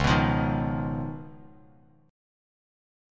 C#9 Chord
Listen to C#9 strummed